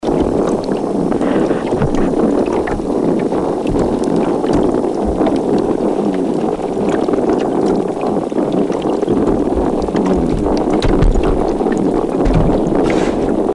Spotted Seatrout
The seatrout makes a drumming or purring sound.
All drums are able to make a loud drumming or croaking sound by vibrating their swim bladder using special muscles.
spotted-seatrout-call.mp3